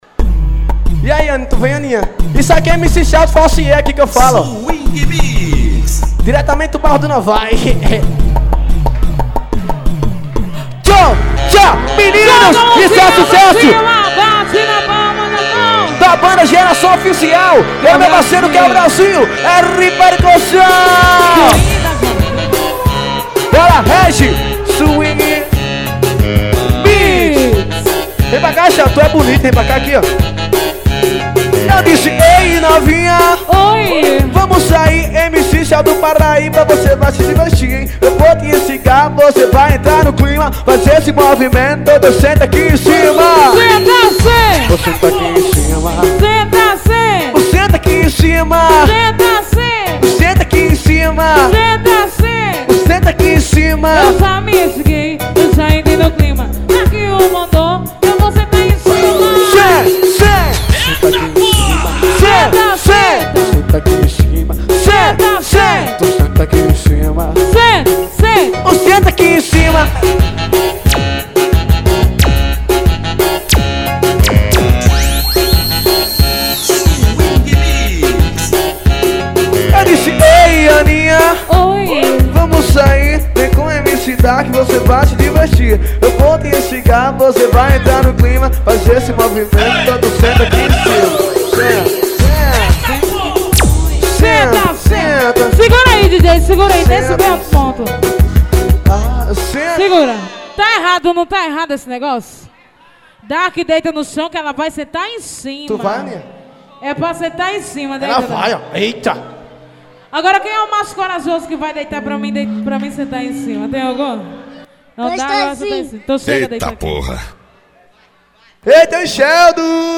AO VIVO..